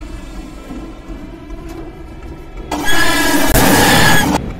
Huggy Wuggy Inside Train Tunnel